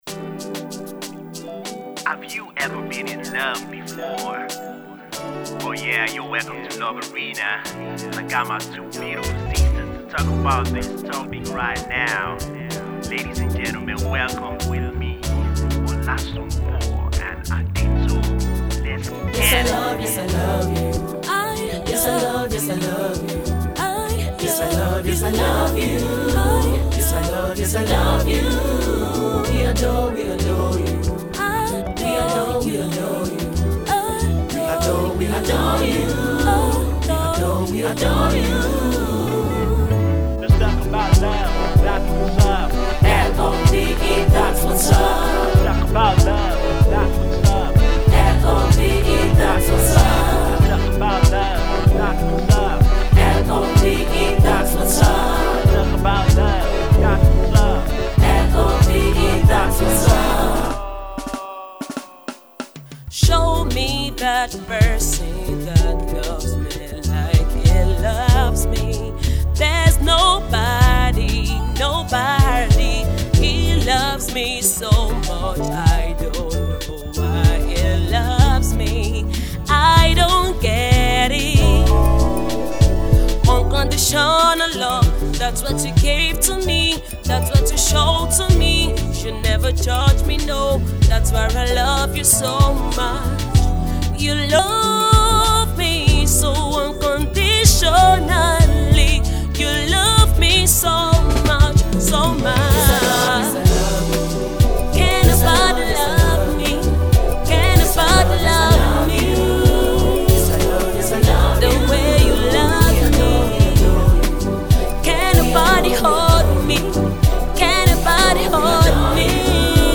a classy tune that fuses funk, Jazz, and Gospel elements